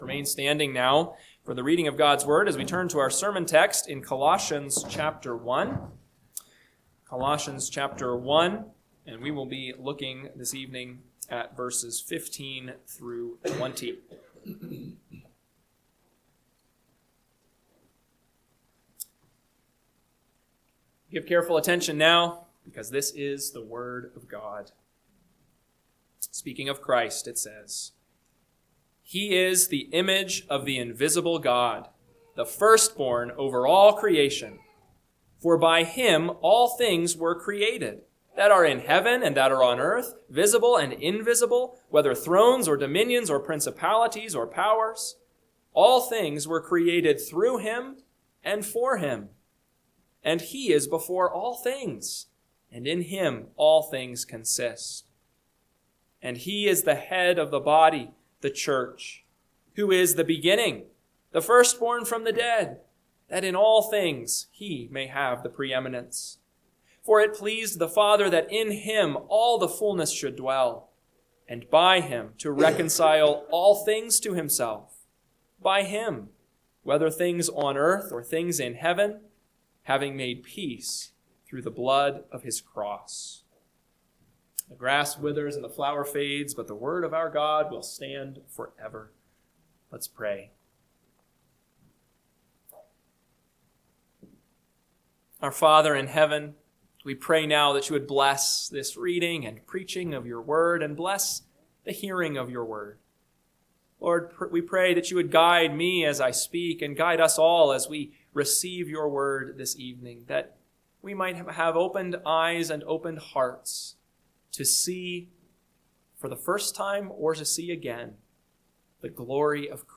PM Sermon – 1/18/2026 – Colossians 1:15-20 – Northwoods Sermons